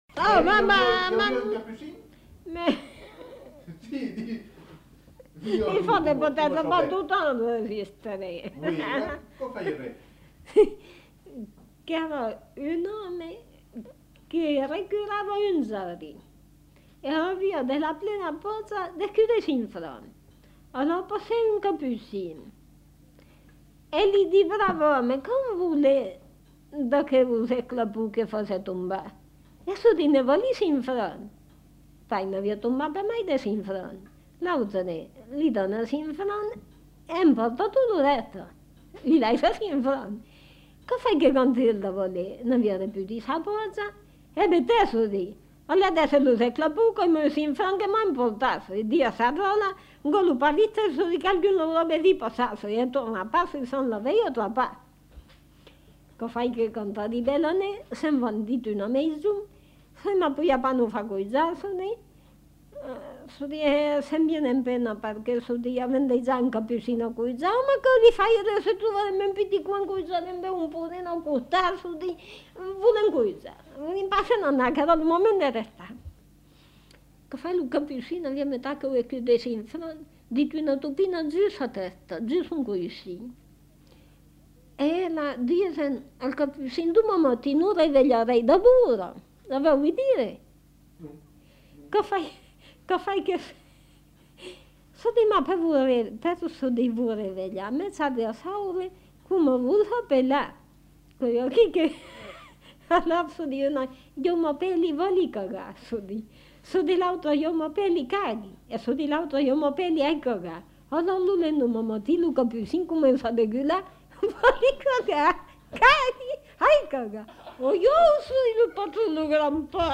Lieu : La Chapelle-Aubareil
Genre : conte-légende-récit
Type de voix : voix de femme
Production du son : parlé
Classification : conte facétieux
Notes consultables : Suivi d'un fragment de chant.